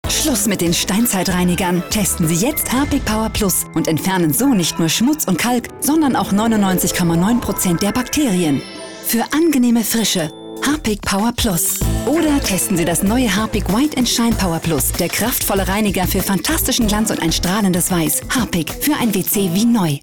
Deutsche Sprecherin für Funkspots, TV-Spots, Industriefilm, Warteschleife, Anrufbeantworter. Stimmlage: mittel, weich Stimmalter: 25-40
Sprechprobe: Industrie (Muttersprache):